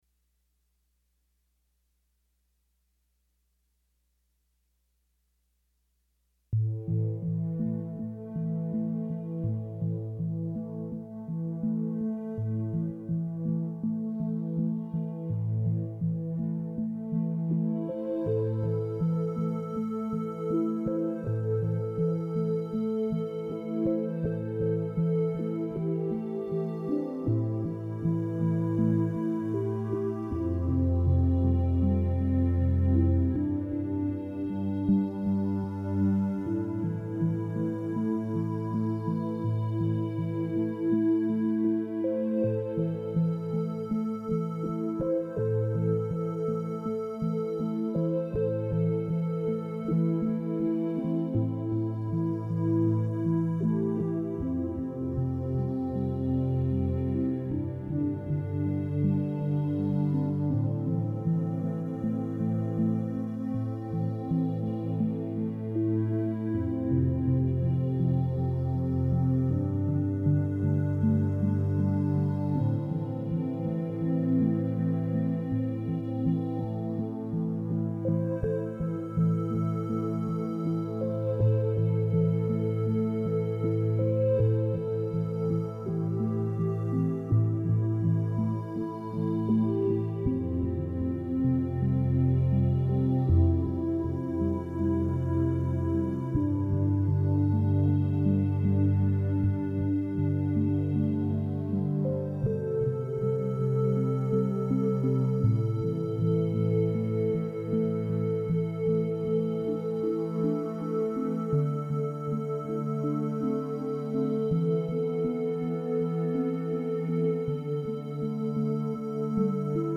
It’s a first generation improv piece. I liked the liquidy e. piano sound, the piece seemed to write itself based on the sound.